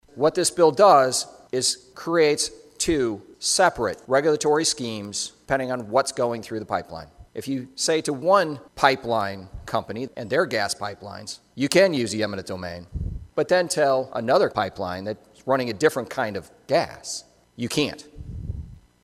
REPUBLICAN REPRESENTATIVE BRIAN LOHSE OF BONDURANT SAYS THE IOWA AND U-S CONSTITUTIONS REQUIRE THAT LAWS APPLY EQUALLY TO ALL THOSE IMPACTED AND THAT’S WHY HE CAN’T SUPPORT THE BILL.